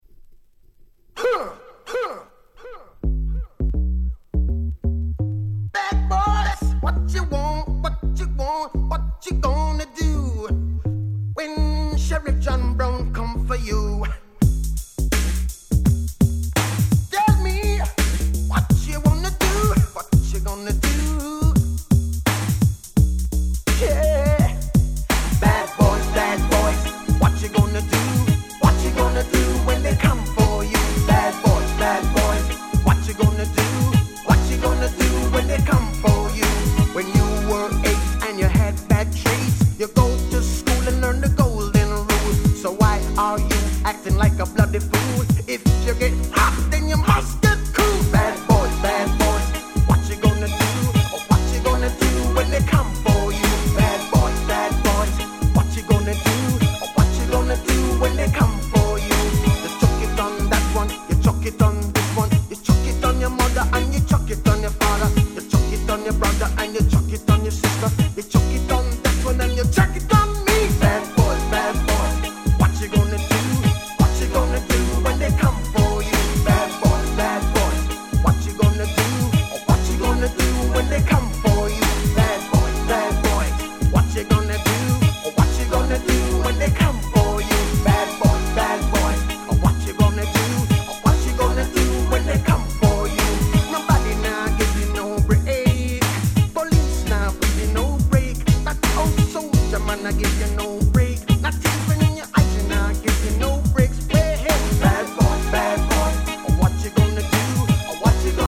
93' Smash Hit Reggae !!
上記の2曲と比べてしまうと若干地味ではございますが、こちらもCoolで非常に格好良い1曲です。